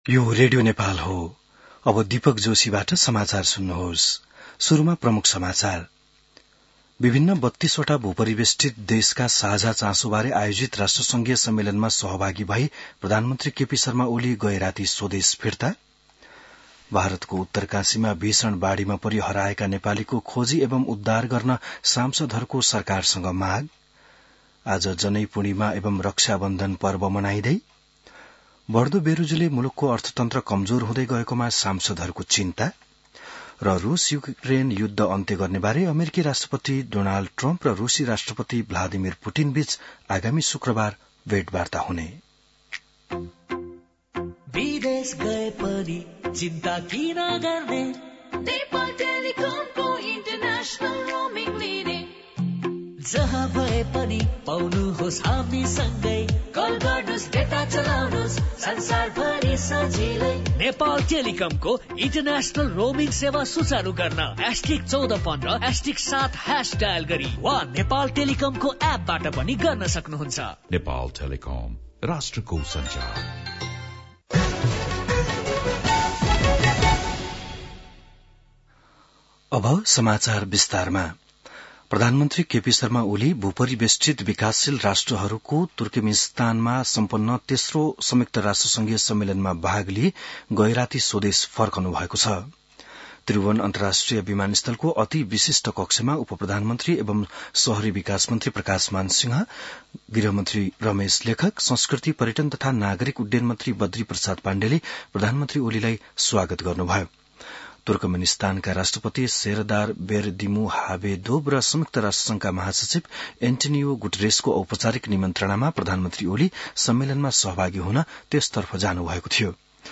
बिहान ७ बजेको नेपाली समाचार : २४ साउन , २०८२